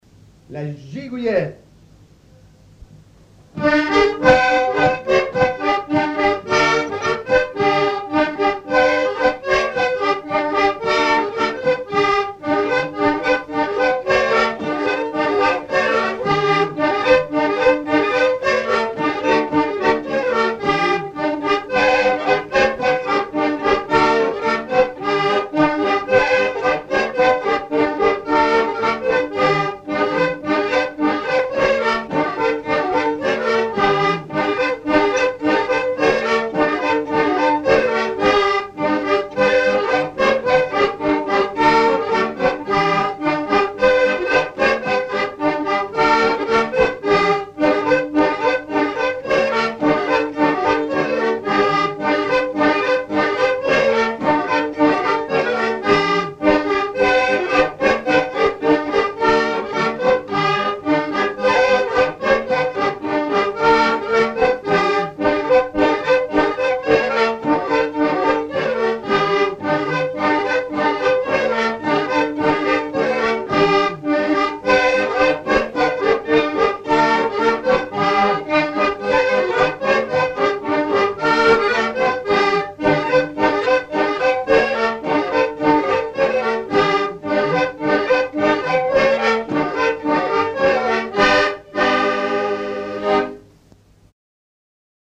Chants brefs - A danser
danse : gigouillette
Pièce musicale inédite